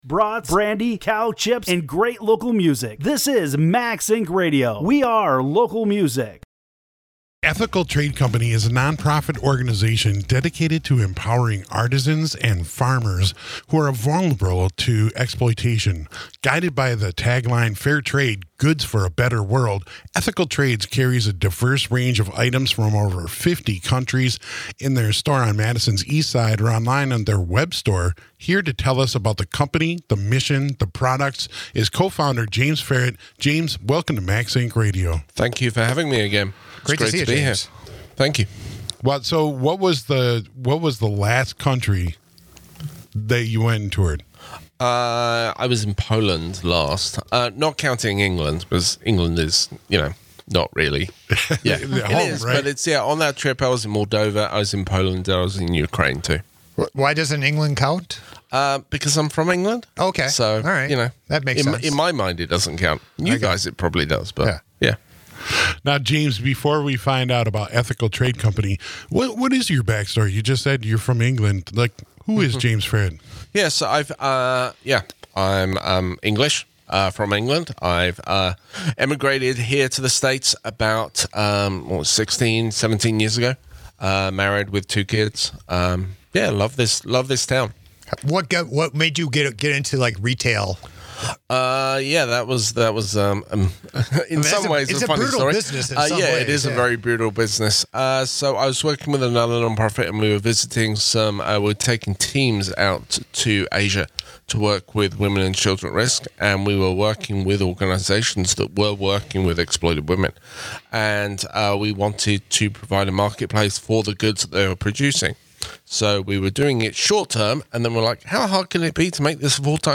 is in studio to talk about the company's mission, successes, store and products.